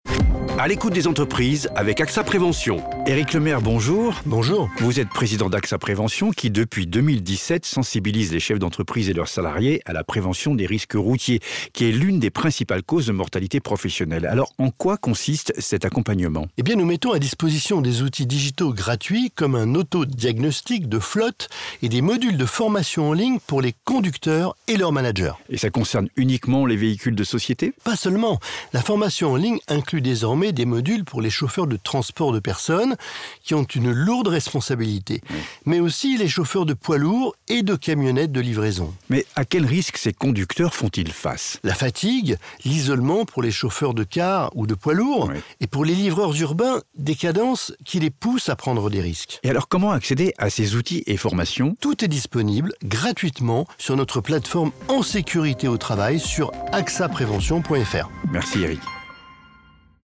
Chroniques radio